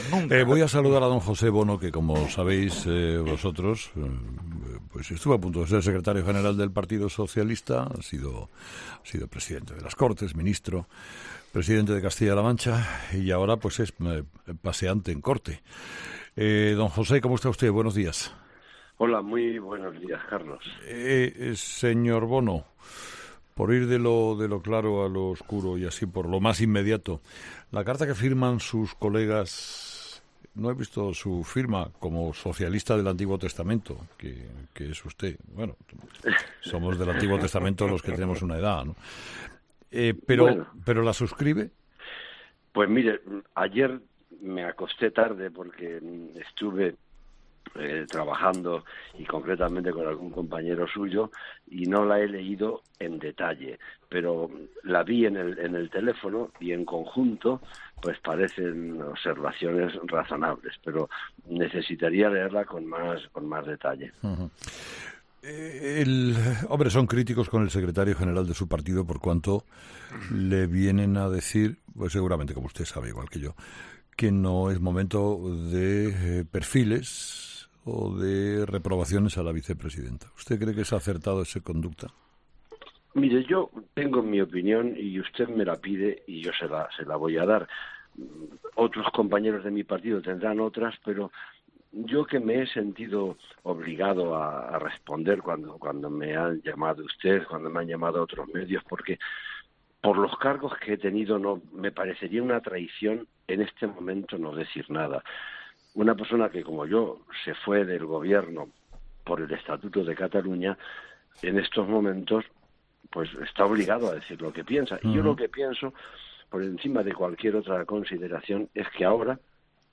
Escucha la entrevista a el exministro José Bono